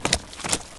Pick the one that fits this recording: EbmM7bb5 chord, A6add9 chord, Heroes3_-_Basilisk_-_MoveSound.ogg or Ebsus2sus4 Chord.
Heroes3_-_Basilisk_-_MoveSound.ogg